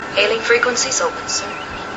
Hailing frequencies open, sir (different inflection)